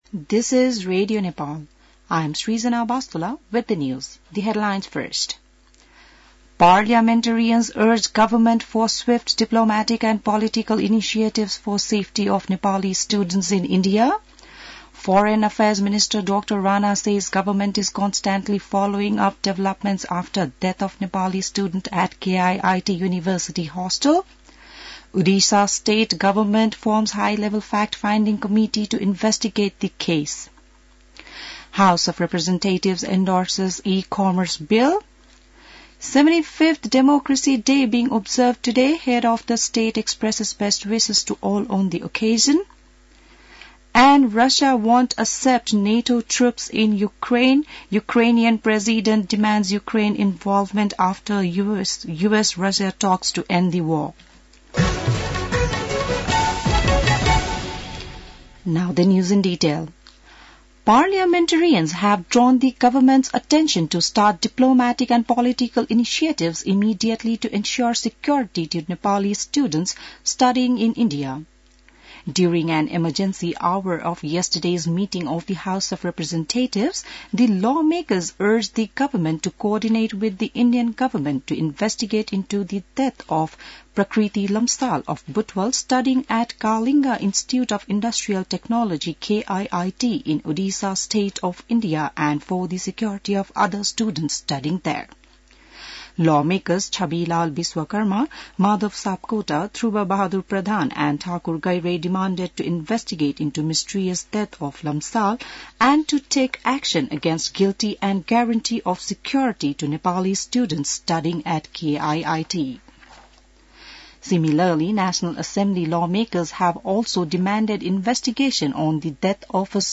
बिहान ८ बजेको अङ्ग्रेजी समाचार : ८ फागुन , २०८१